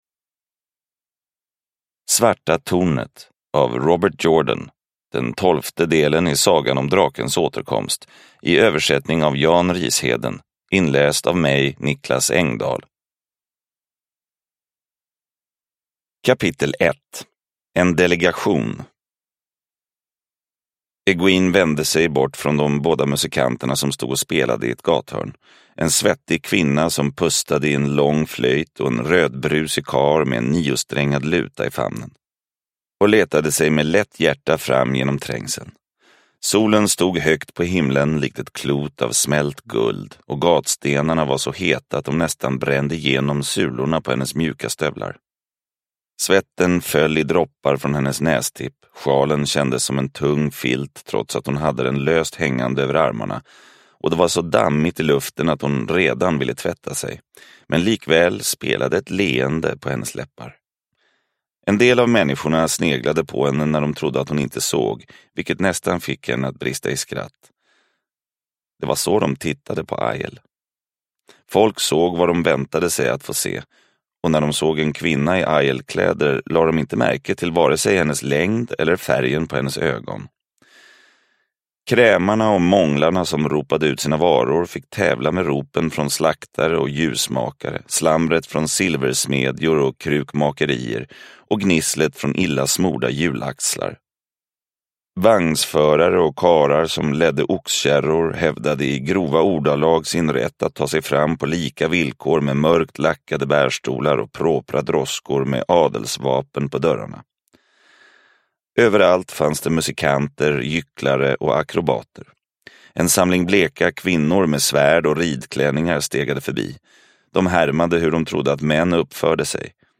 Svarta tornet – Ljudbok – Laddas ner
Uppläsare: